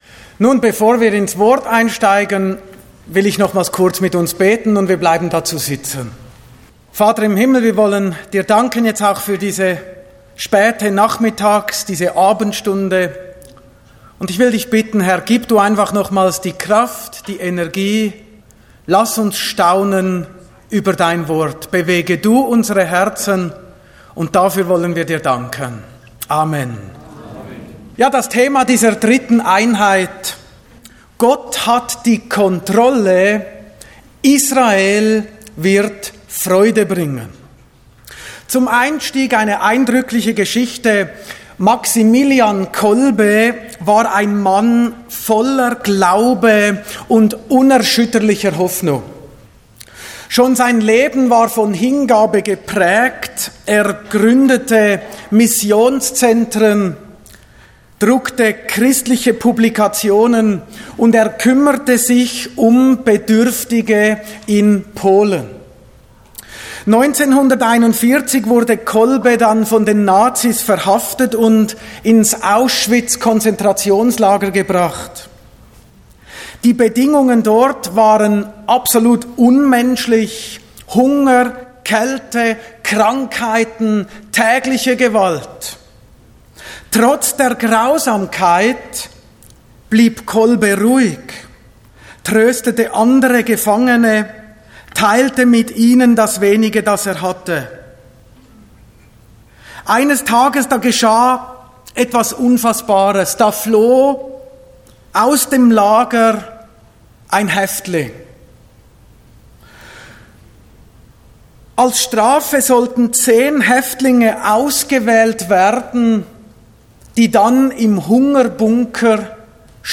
Botschaft Zionshalle https